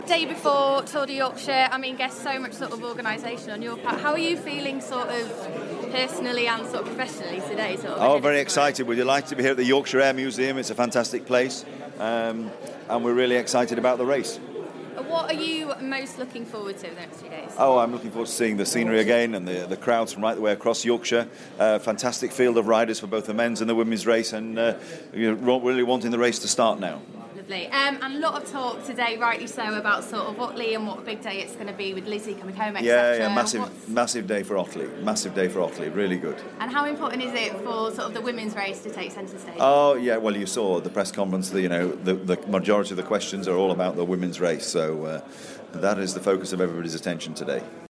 Sir Gary Verity talks to Stray FM on the eve of the Tour de Yorkshire